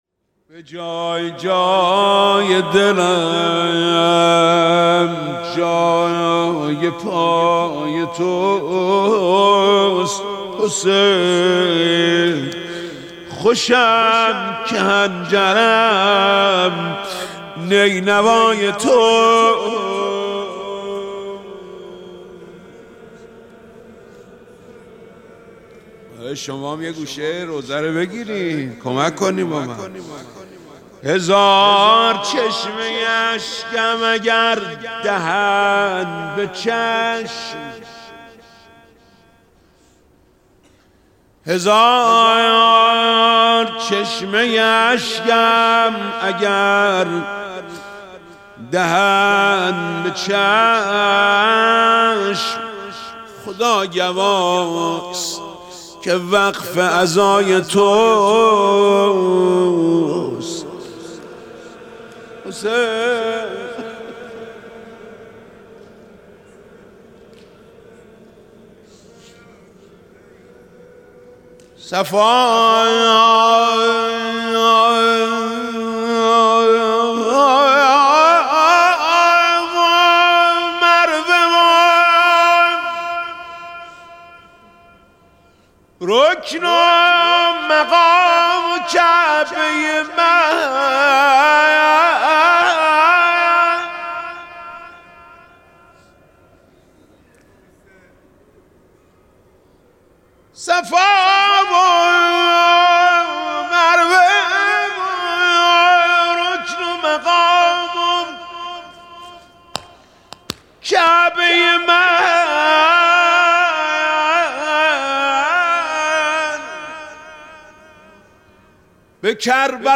مراسم مناجات شب بیست‌و‌پنجم ماه شعبان ۱۴۰۰